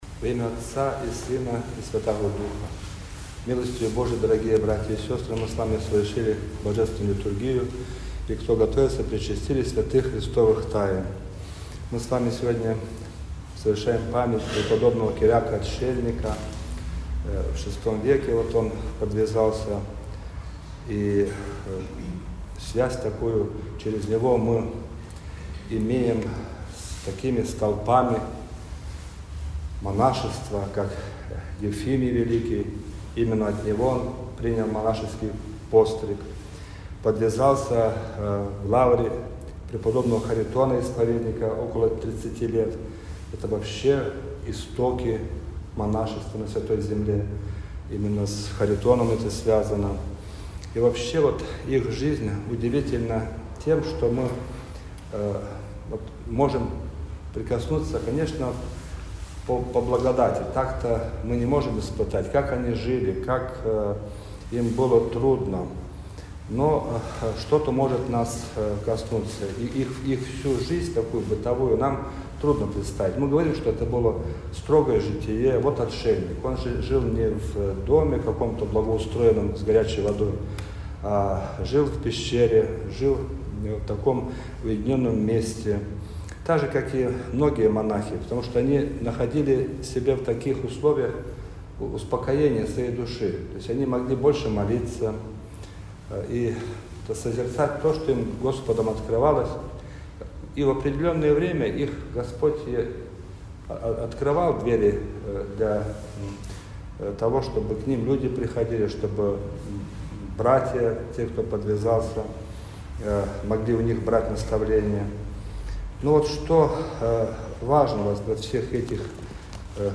Проповедь.mp3